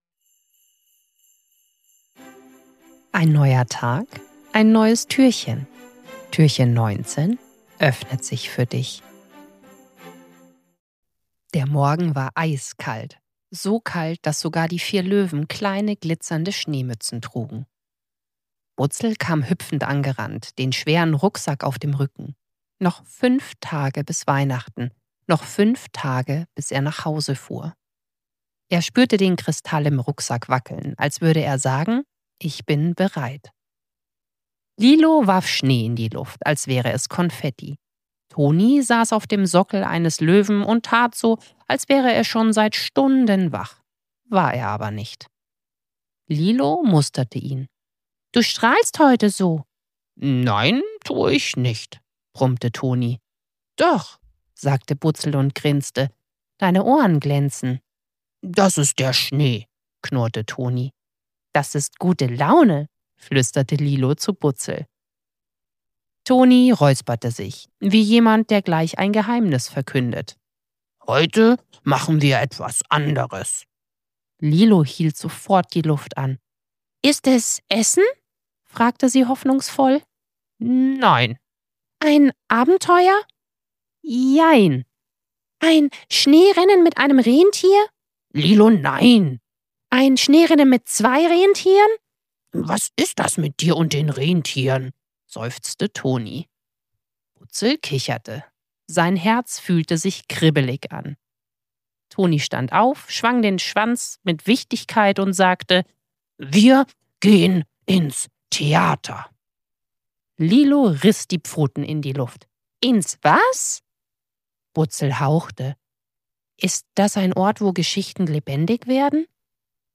19. Türchen – Butzel und die Geschichten, die lebendig werden ~ Butzels Adventskalender – 24 Hörgeschichten voller Herz & kleiner Wunder Podcast